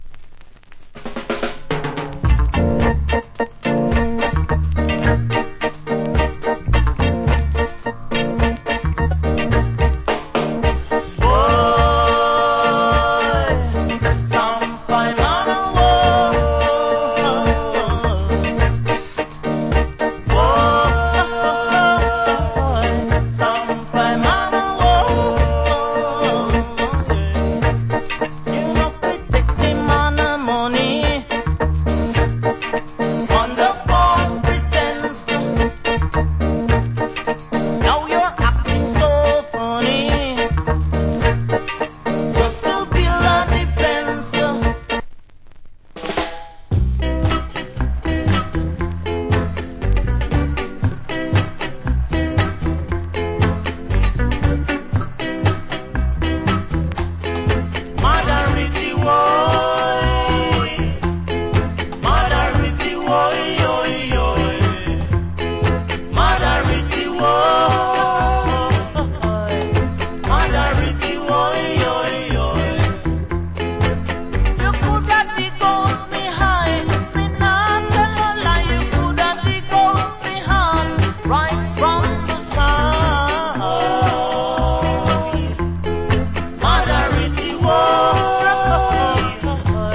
Old Hits Early Reggae.